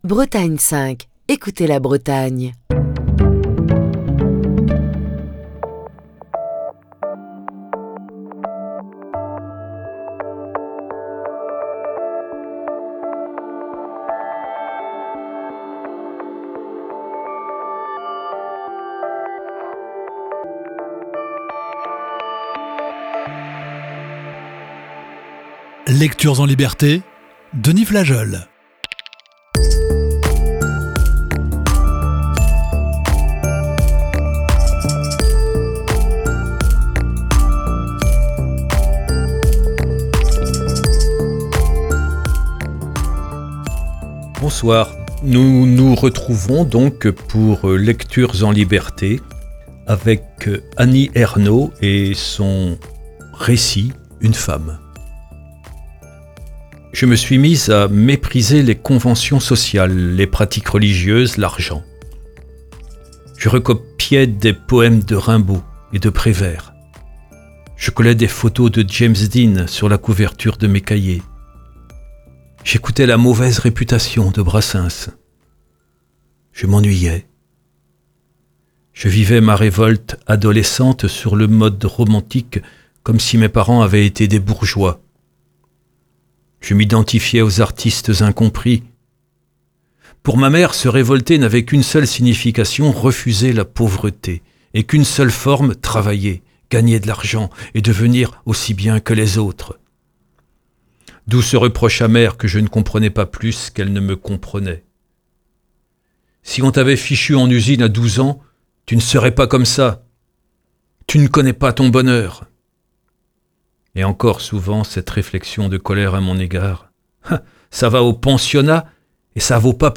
Émission du 15 février 2024.